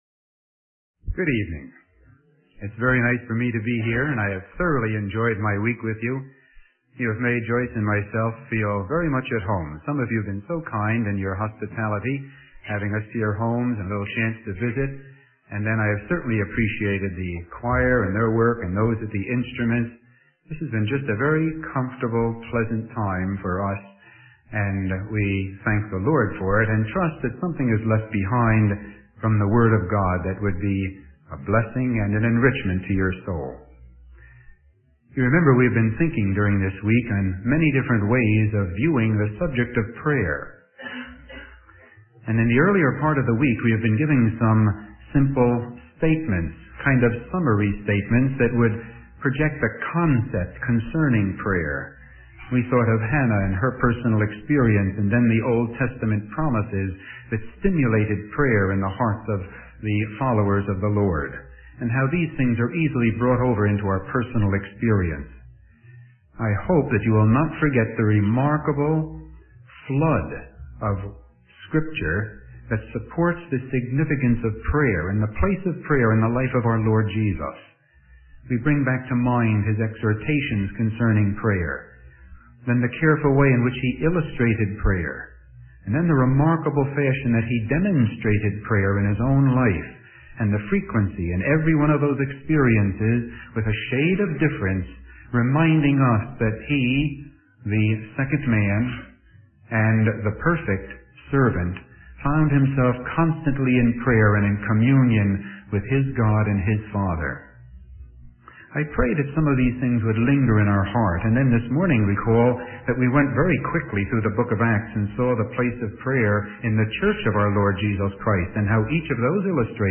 In this sermon, the speaker focuses on the importance of possessing rest and inheritance as God's people.